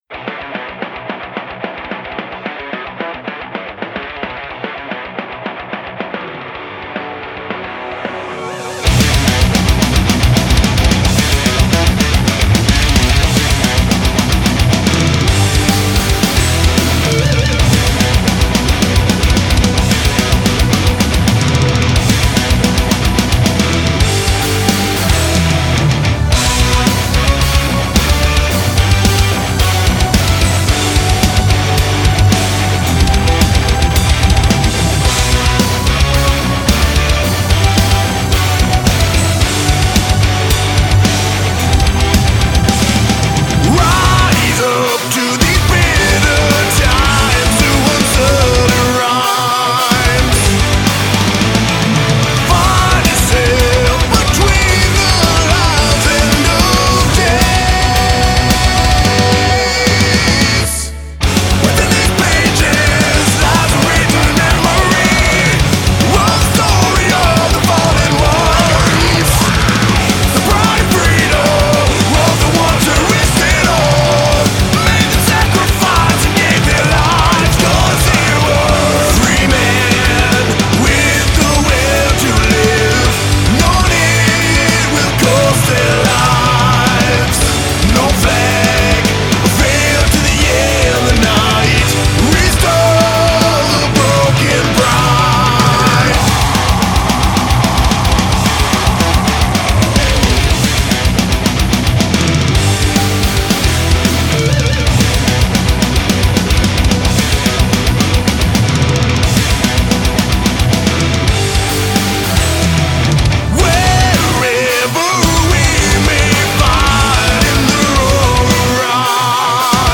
Canadian power metal act